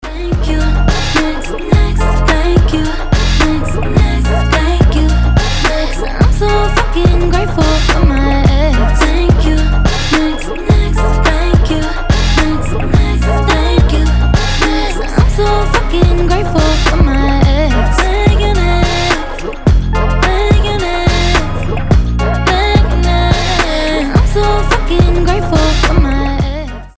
• Качество: 320, Stereo
поп
RnB
красивый женский голос
озорные